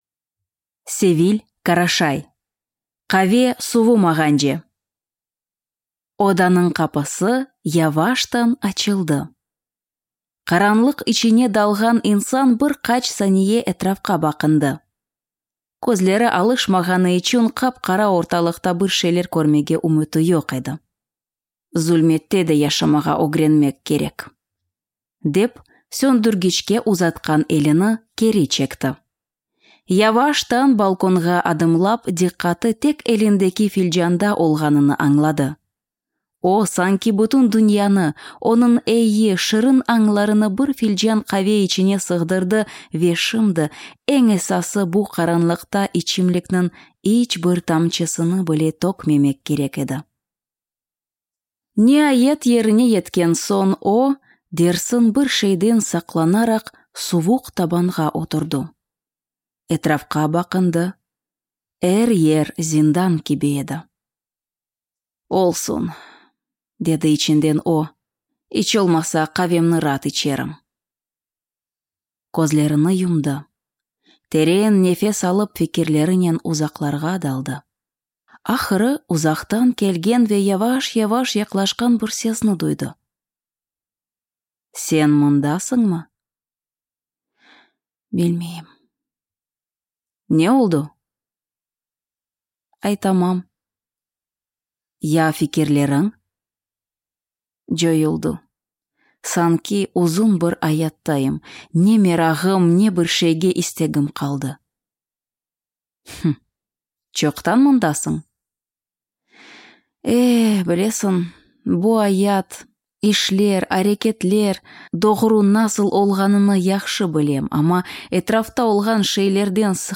Аудиокнига Къаве сувумагъандже | Библиотека аудиокниг